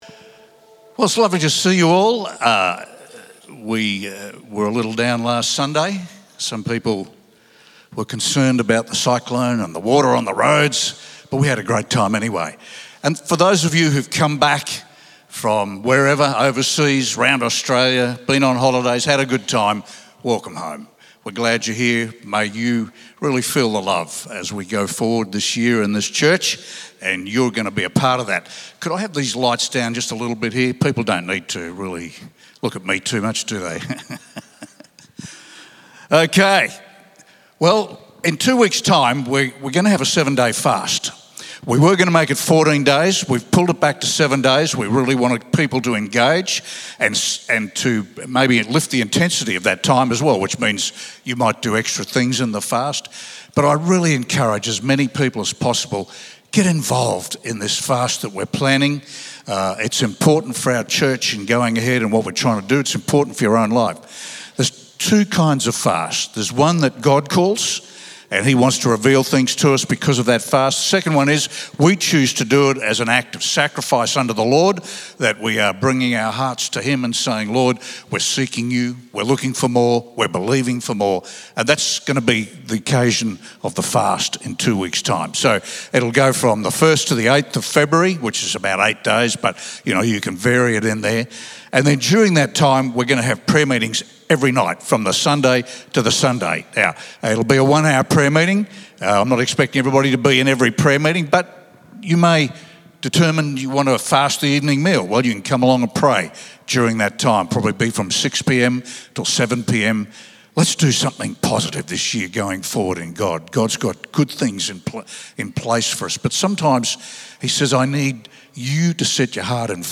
Weekly Sermons